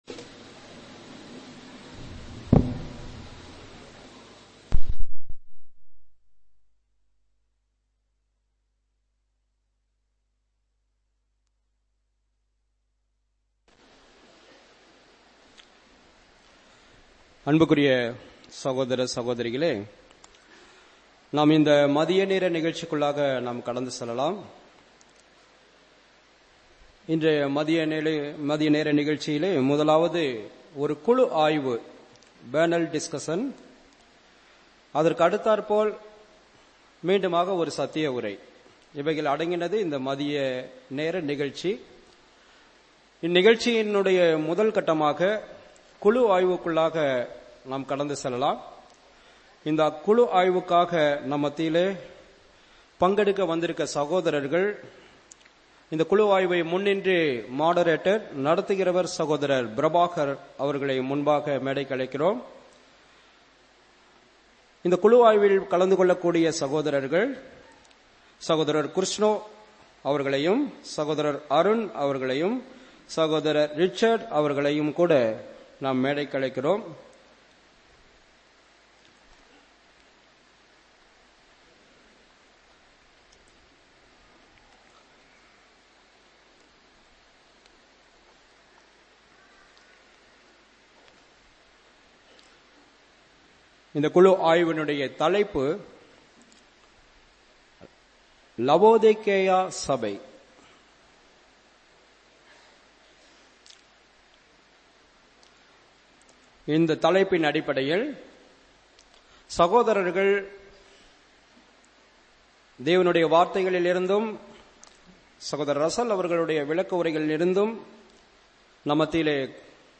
Index of /Tamil_Sermons/2017_COIMBATORE_CONVENTION_-_MAY
L3_PANEL_DISCUSSION_-_LAODICEAN.mp3